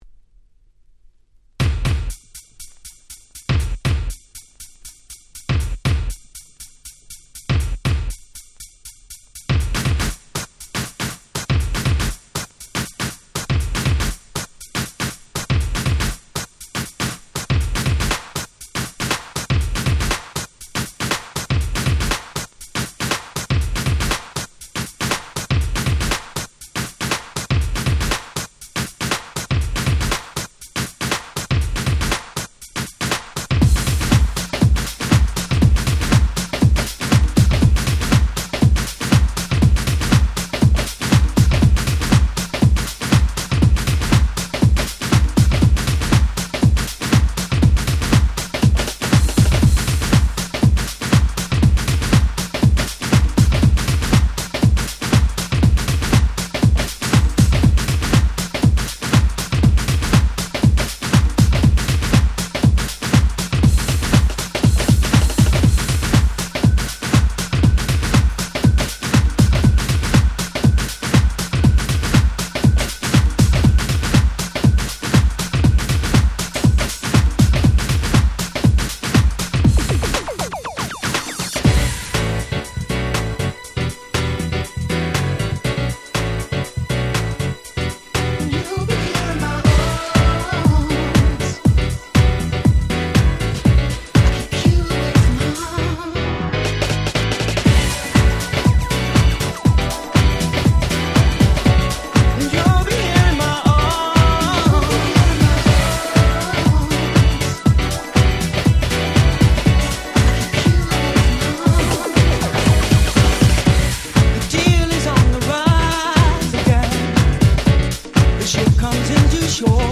97' Nice Vocal House !!
R&Bフリークにも自信を持ってオススメ出来る最高の歌物Houseです！！